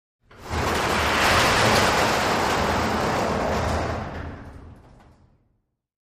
Garage Door
fo_garagedr_lg_open_02_hpx
Large and small garage doors are opened and closed.